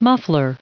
added pronounciation and merriam webster audio
520_muffler.ogg